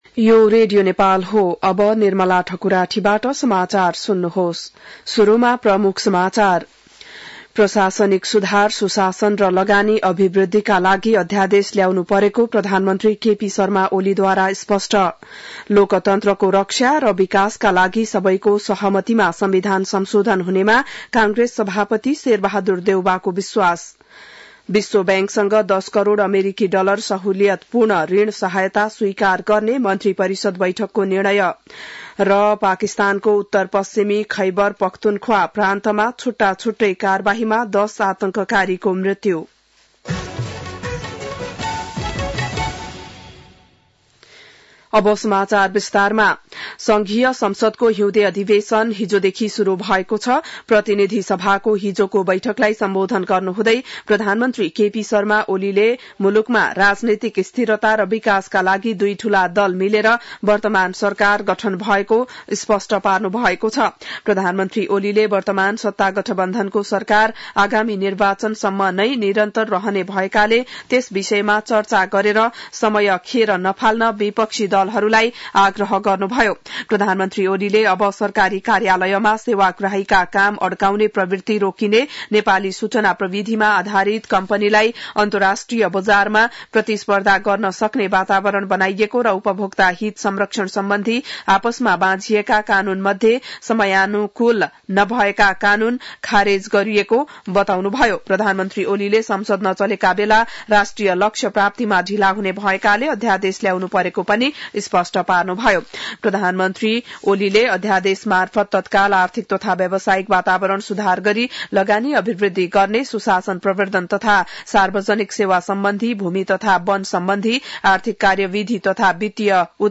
बिहान ९ बजेको नेपाली समाचार : २० माघ , २०८१